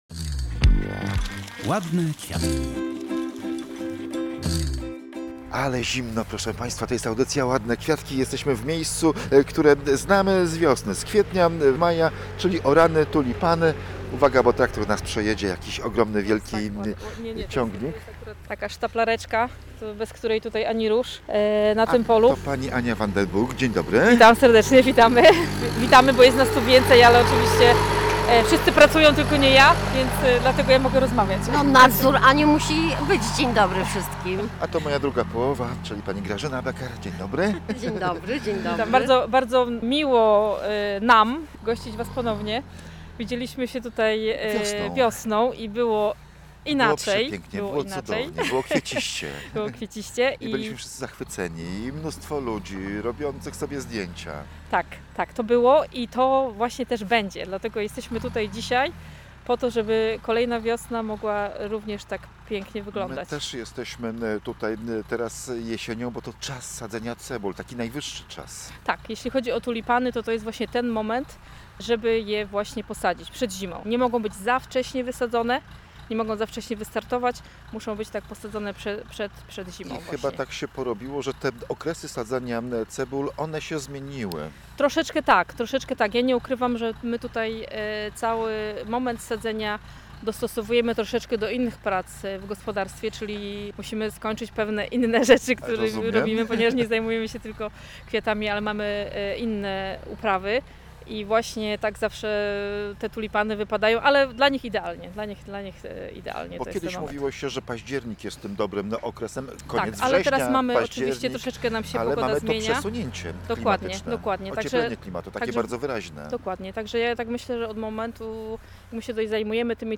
W audycji „Ładne Kwiatki” odwiedzamy Błotnik na Żuławach.
Jak sadzi się tulipany na Żuławach, opowiada ekipa pracująca na polu.